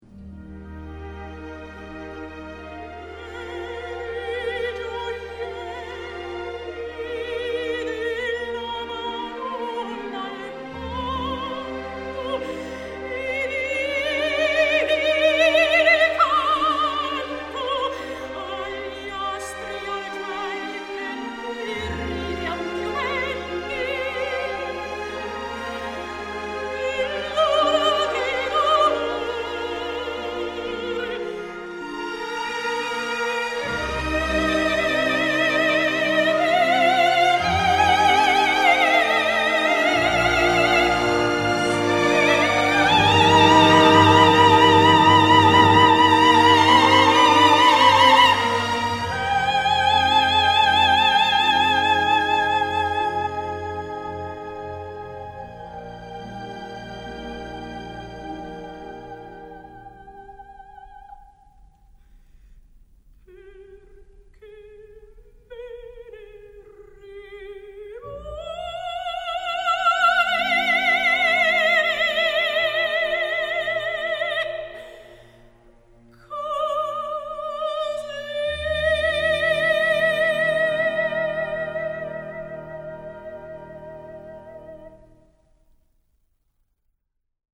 Aria: